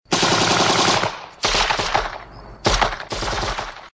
MACHINE GUN FIRES.mp3
Original creative-commons licensed sounds for DJ's and music producers, recorded with high quality studio microphones.
Channels Stereo
macine_gun_fires_kc6.ogg